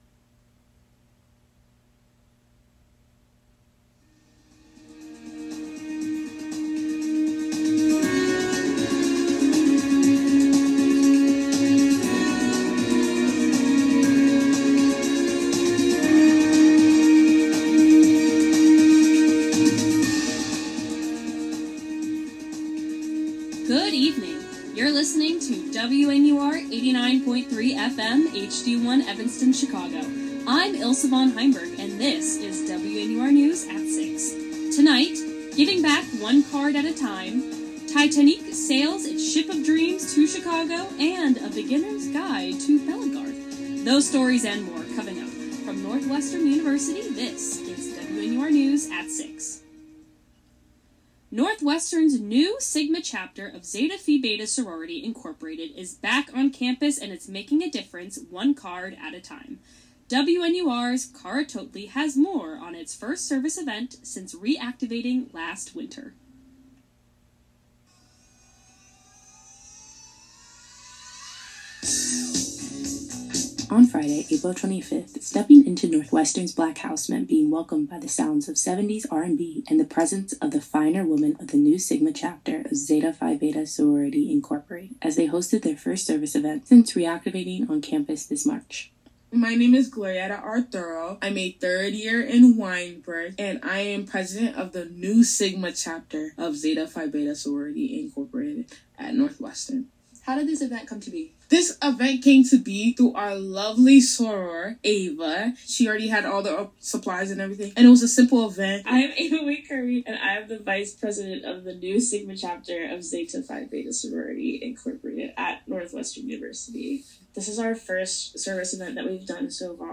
April 30, 2025: An off-broadway version of Titanic, a new Northwestern sorority chapter, foam weapon fighting, and poems for spring. WNUR News broadcasts live at 6 pm CST on Mondays, Wednesdays, and Fridays on WNUR 89.3 FM.